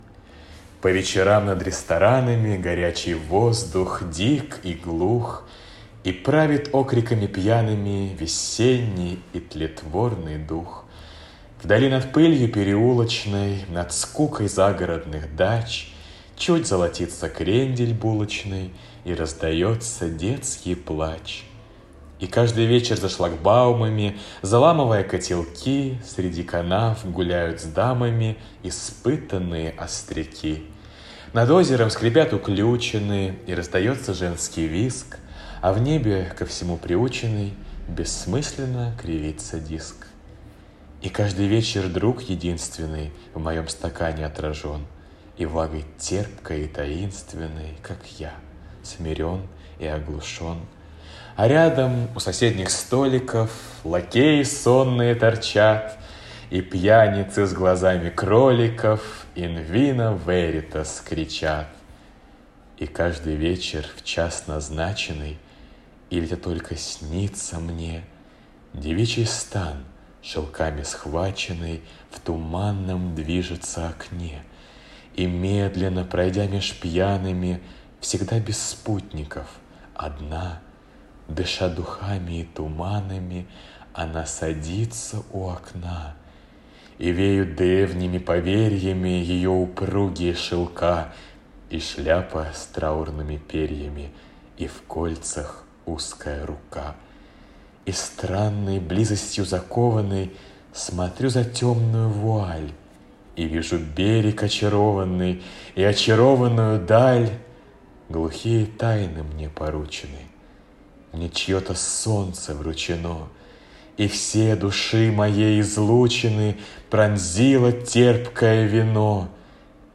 Певческий голос Бас